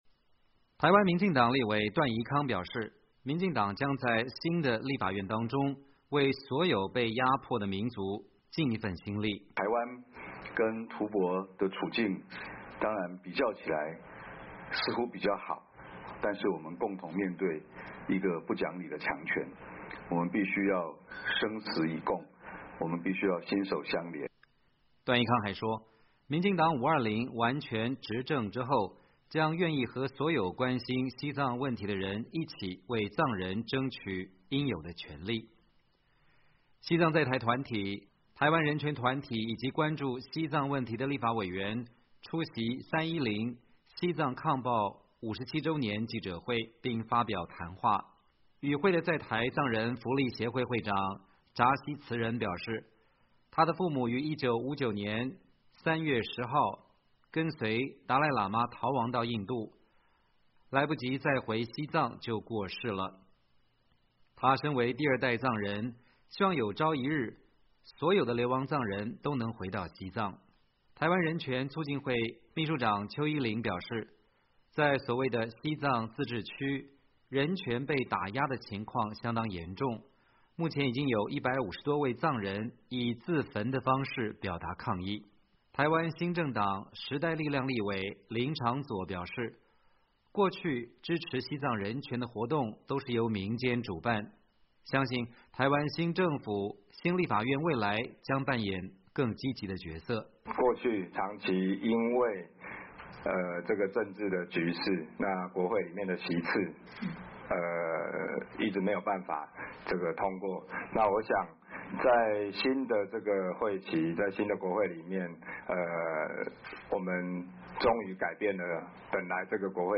西藏在台团体、台湾人权团体以及关注西藏问题的立法委员出席310西藏抗暴57周年记者会，并发表谈话。